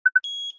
Keycard_Granted.wav